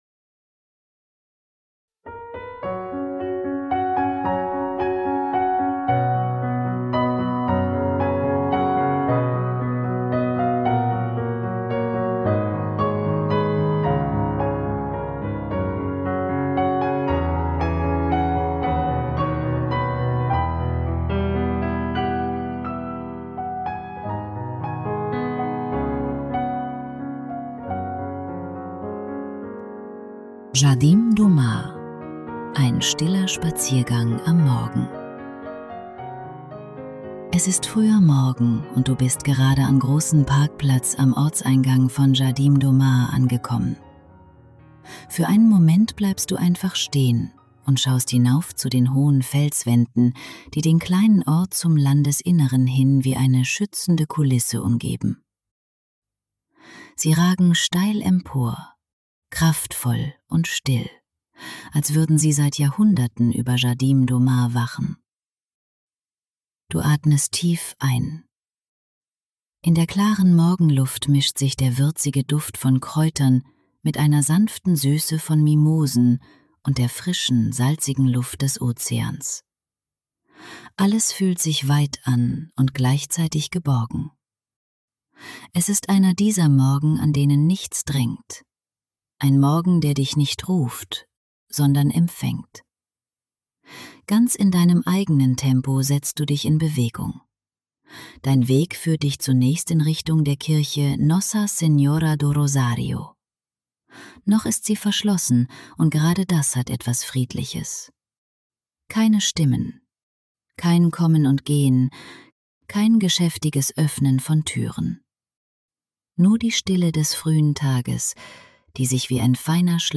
These audio walks take you to Madeira – guiding you step by step through peaceful, living places.
Jardim-do-Mar_final.mp3